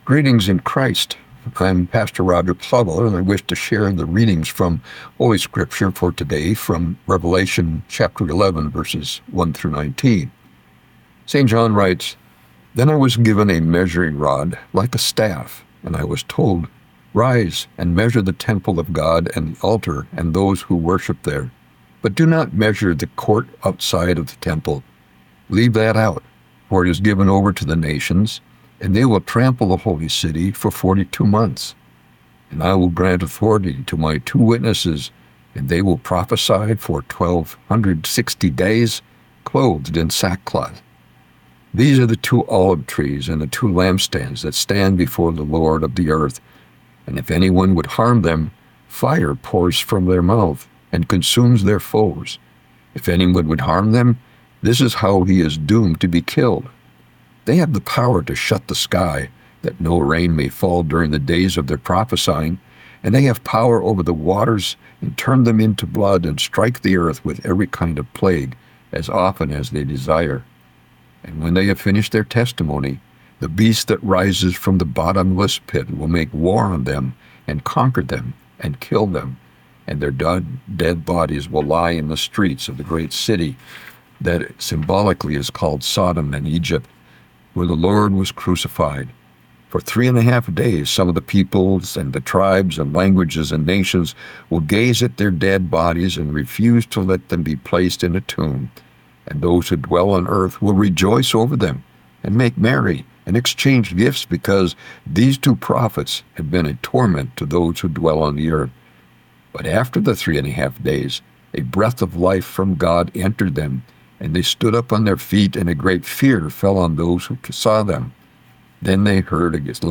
Morning Prayer Sermonette: Revelation 11:1-19
Hear a guest pastor give a short sermonette based on the day’s Daily Lectionary New Testament text during Morning and Evening Prayer.